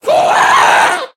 Minecraft Version Minecraft Version snapshot Latest Release | Latest Snapshot snapshot / assets / minecraft / sounds / mob / ghast / scream4.ogg Compare With Compare With Latest Release | Latest Snapshot
scream4.ogg